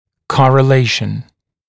[ˌkɔrə’leɪʃn][ˌкорэ’лэйшн]соотношение